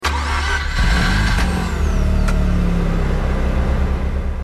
Home gmod sound vehicles tdmcars rx8
enginestart.mp3